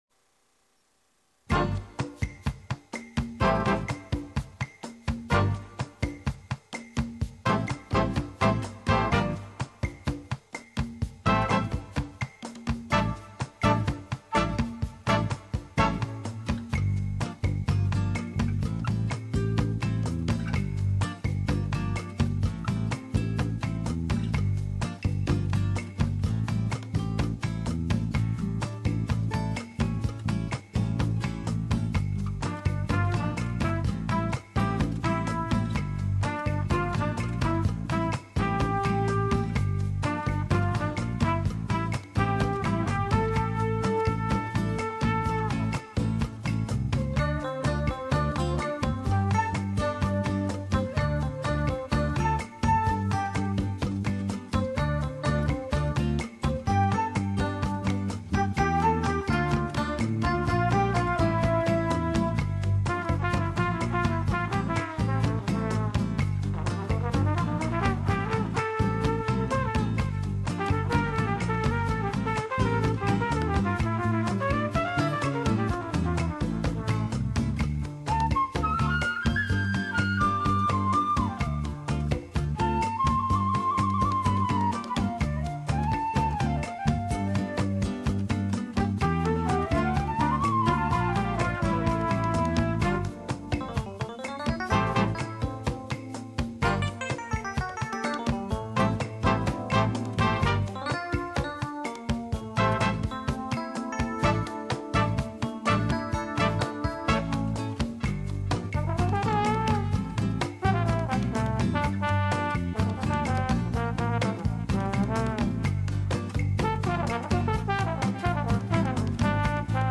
Elevator Music [Refreshing].mp3